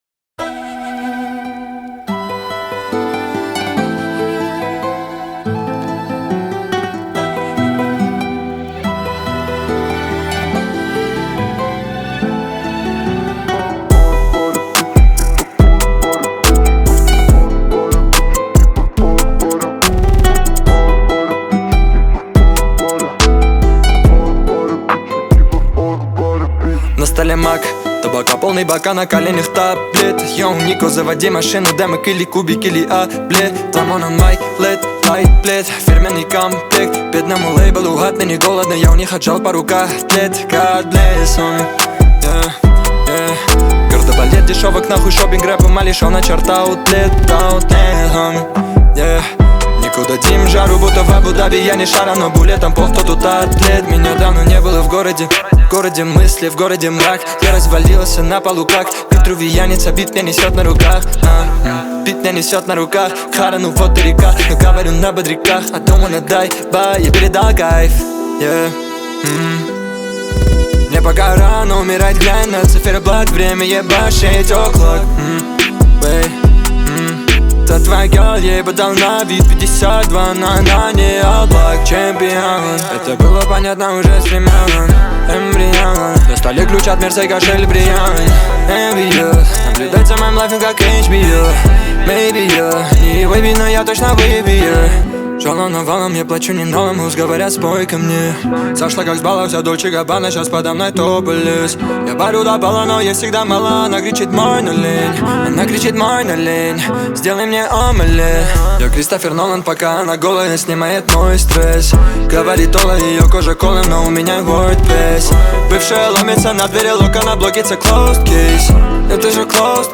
Жанр: Узбекские песни